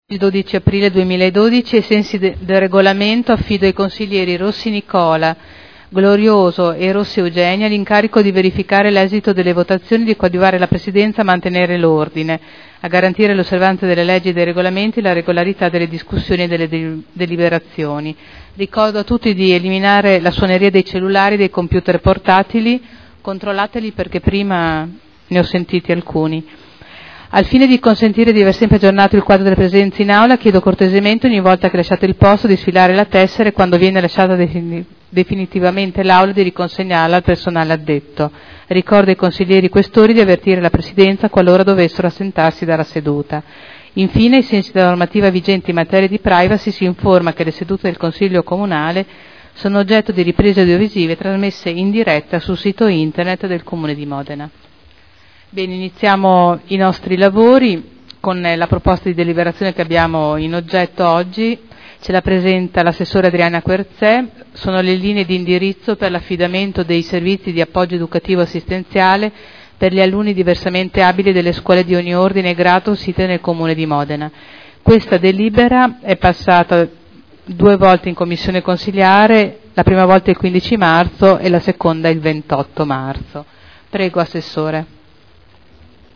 Seduta del 12/04/2012. Il Presidente Caterina Liotti apre i lavori del Consiglio.